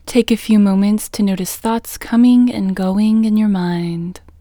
WHOLENESS English Female 2